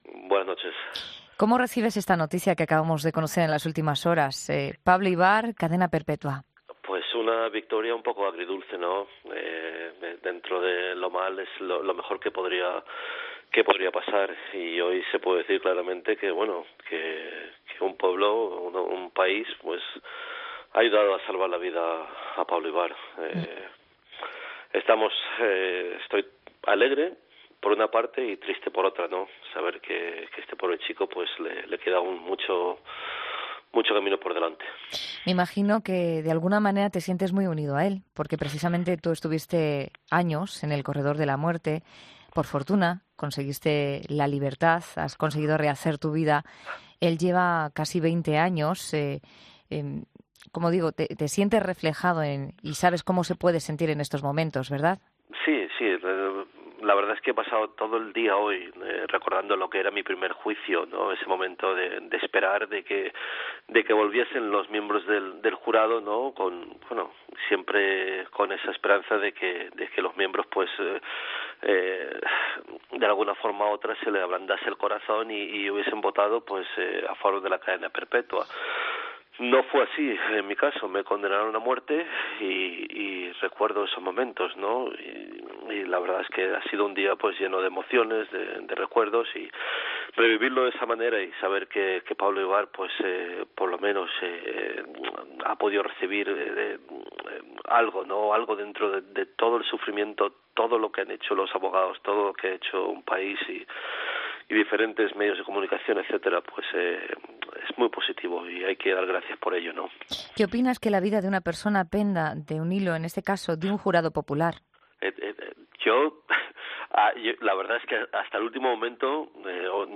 Joaquín José Martínez, el primer español en salir del famoso ‘corredor de la muerte’ ha pasado por ‘La Noche de COPE’ tras conocerse que el hispanoamericano Pablo Ibar ha logrado, este miércoles, evitar la pena de muerte.